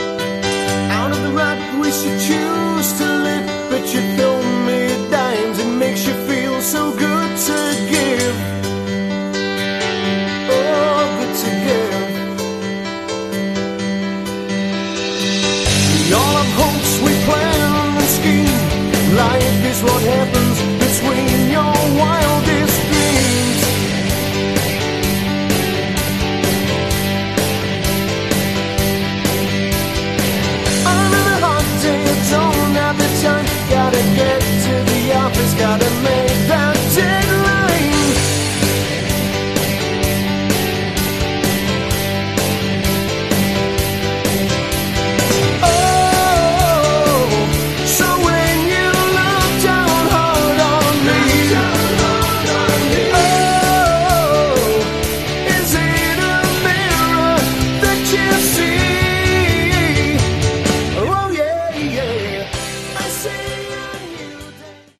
Category: AOR
Straight-ahead British A.O.R. ,No frills,good album..